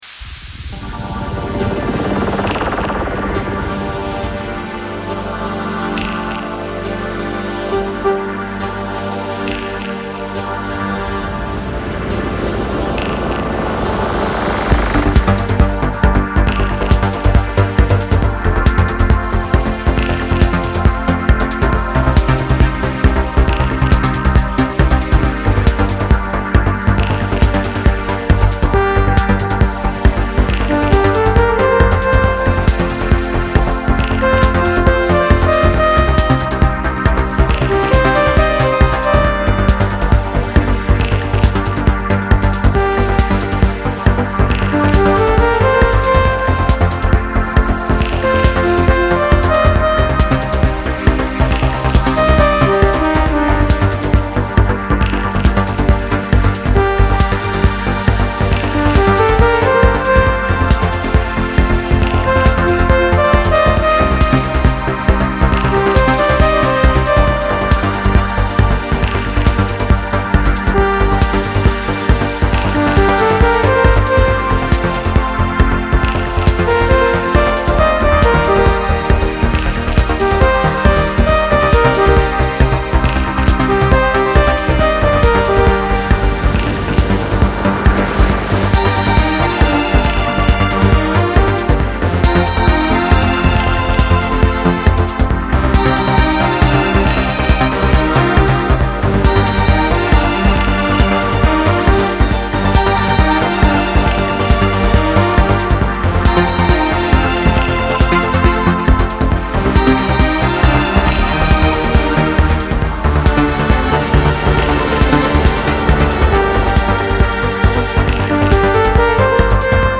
〽 ژانر بی کلام